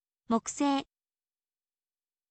mokusei